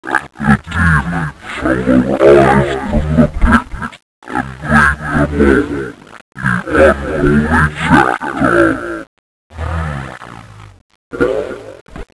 played backwards...